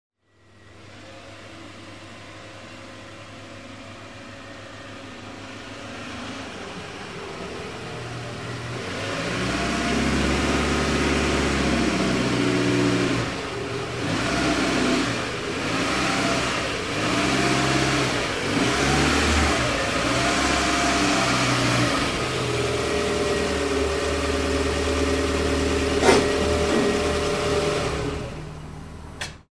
Звуки легкового автомобиля
Звук езды на автомобиле по гаражу или автомастерской (в помещении) (00:30)
в помещении